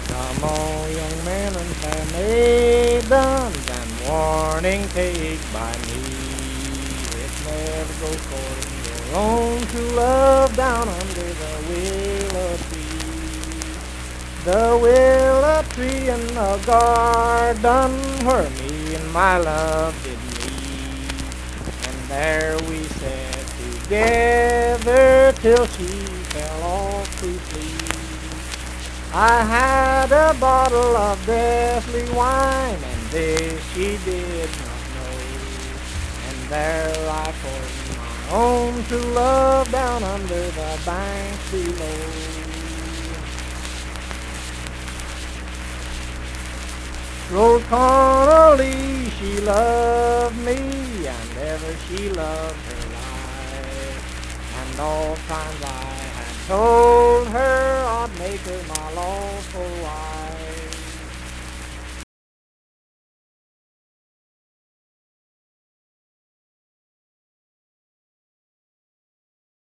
Unaccompanied vocal music
Verse-refrain 4(4).
Voice (sung)
Saint Marys (W. Va.), Pleasants County (W. Va.)